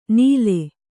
♪ nīle